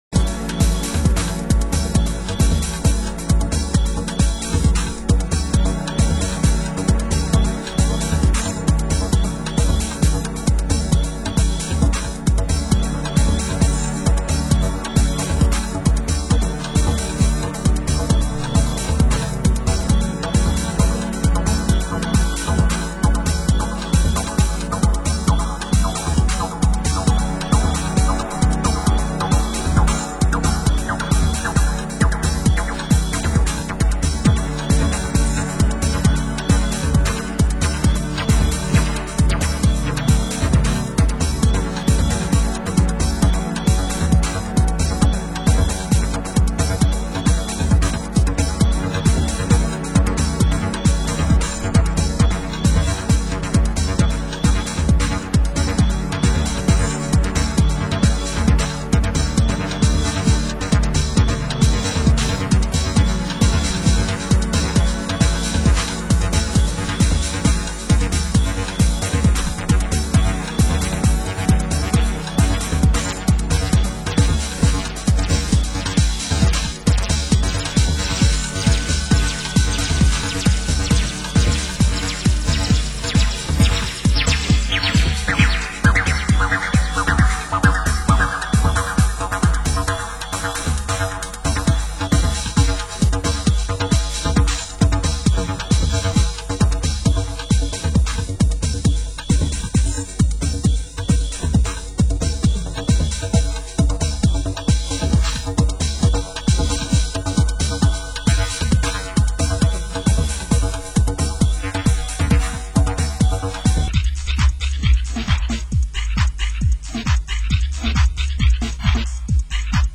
Genre: Acid House